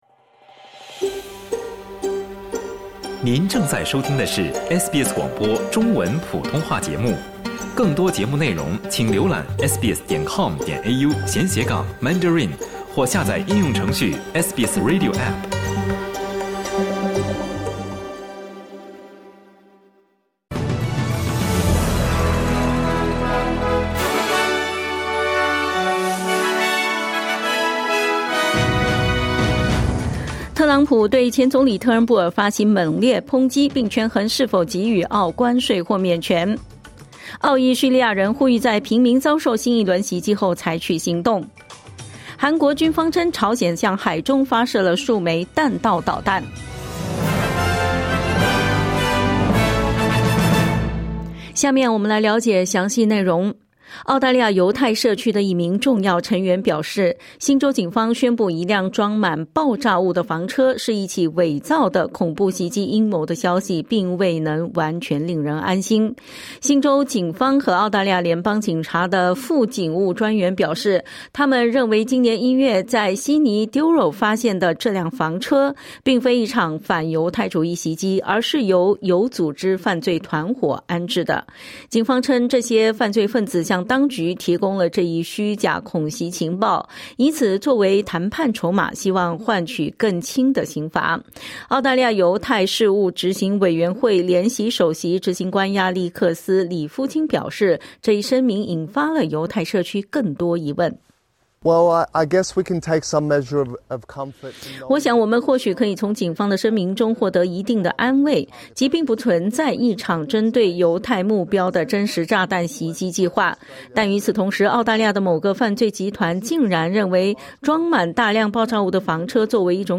SBS早新闻（2025年3月11日）